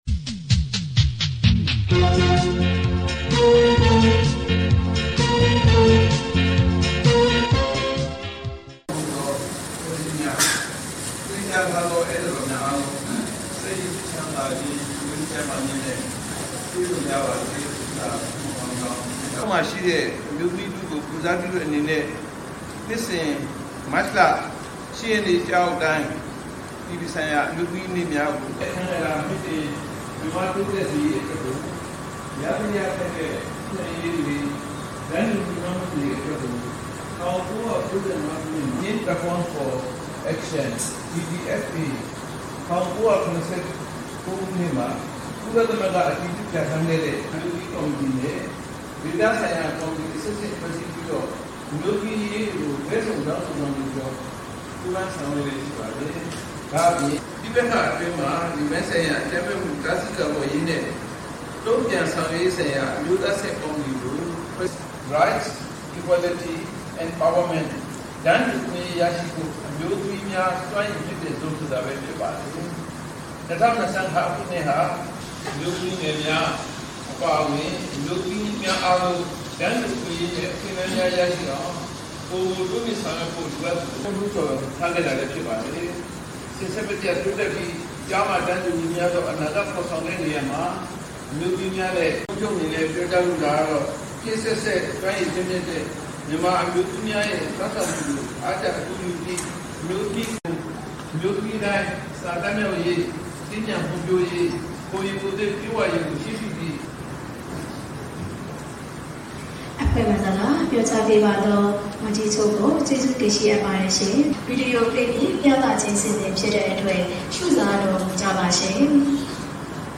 တနင်္သာရီတိုင်းဒေသကြီးဝန်ကြီးချုပ်ဦးမြတ်ကို အပြည်ပြည်ဆိုင်ရာ အမျိုးသမီးများနေ့အခမ်းအနားသို့တက်ရောက် ထားဝယ် မတ် ၈